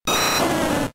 Cri d'Herbizarre ayant un statut dans Pokémon Diamant et Perle.